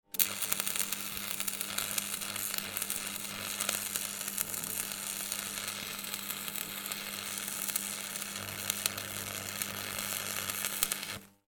Arc Welding Sound Effect
This welding sound effect captures the realistic noise of an electric welding machine as a person works like a blacksmith, welding metal parts with an electrode. Bright sparks and hot metal crackle while the worker is working in an industrial shop, creating a powerful industry atmosphere.
Arc-welding-sound-effect.mp3